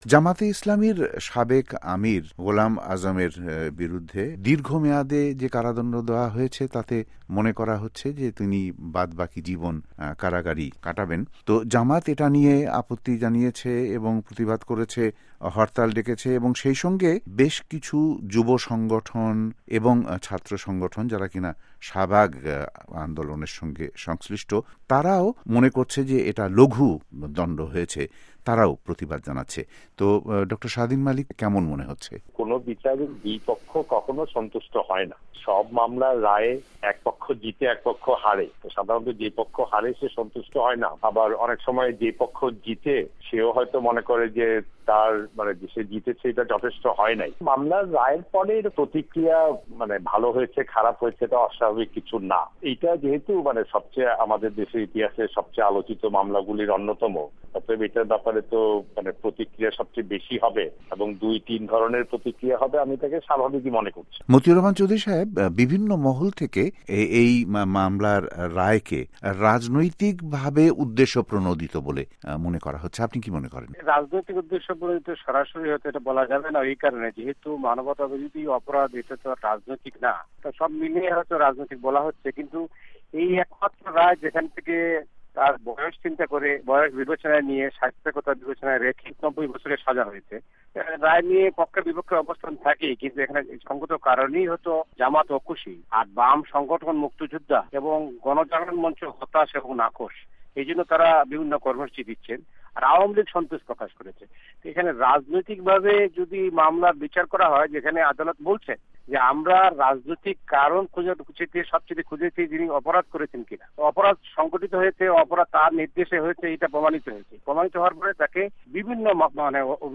বাংলাদেশে আন্তর্জাতিক অপরাধ ট্রাইব্যুনাল জামাতে ইসলামের ৯১ বছর বয়সি সাবেক আমির গোলাম আজমকে ৯০ বছরের কারাদন্ড দিয়েছে – জানাচ্ছেন ঢাকা থেকে